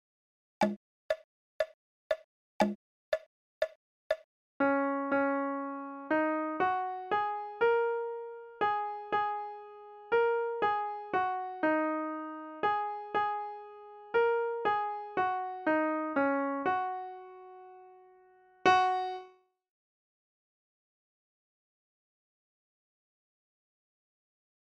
Goes--no staff, black keys